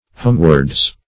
Homeward \Home"ward\, Homewards \Home"wards\, adv. [AS.